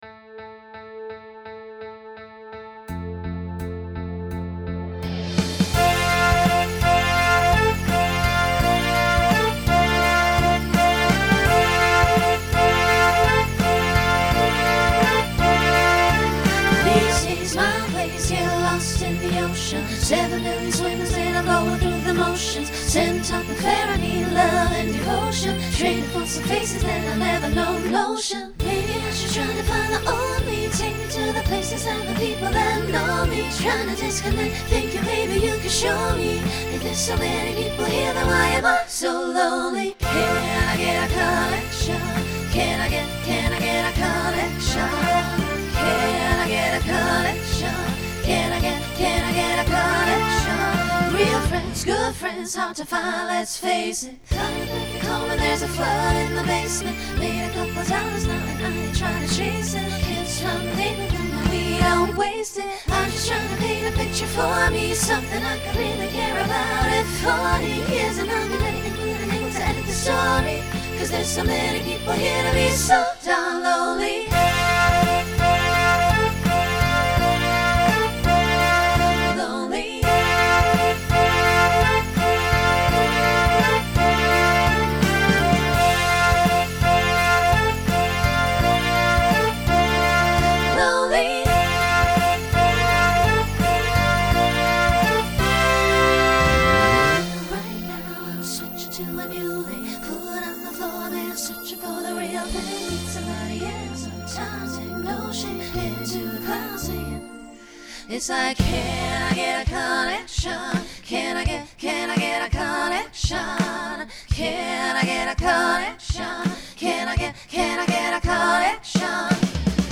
Pop/Dance Instrumental combo
Mid-tempo Voicing SAB